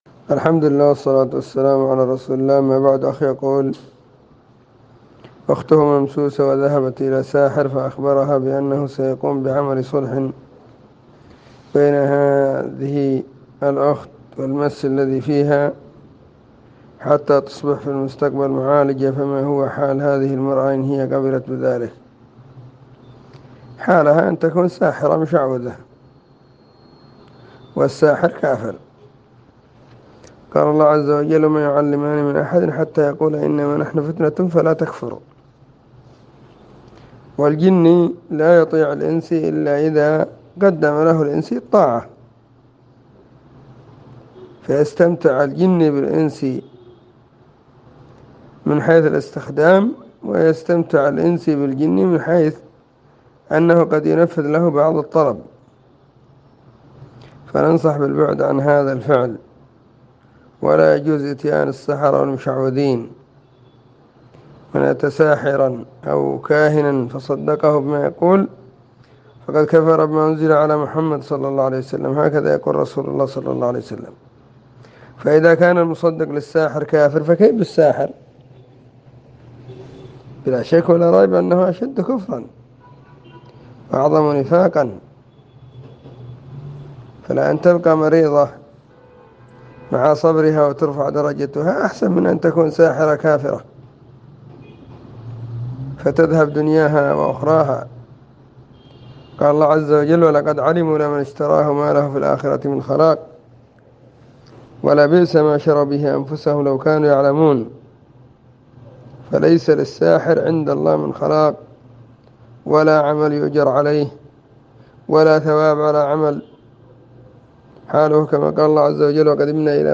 📢 مسجد الصحابة – بالغيضة – المهرة، اليمن حرسها الله.
🎧سلسلة الفتاوى الصوتية المفردة🎧